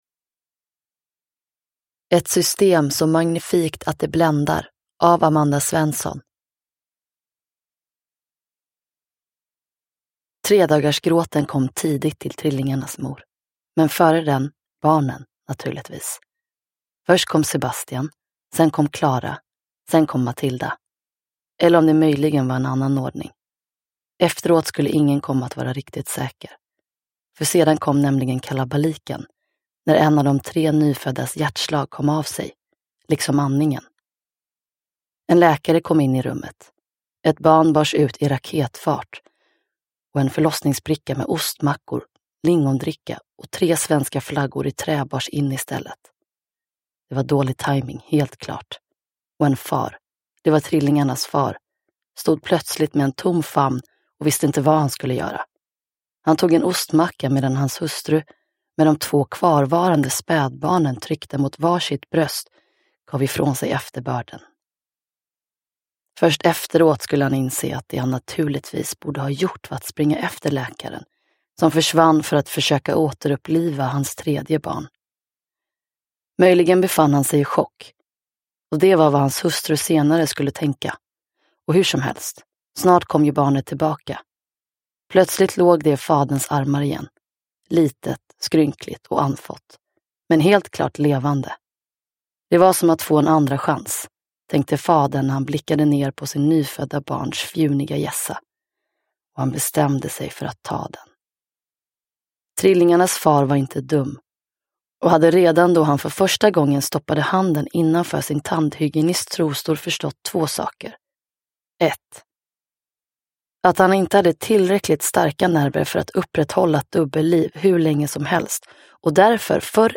Uppläsare: Gizem Erdogan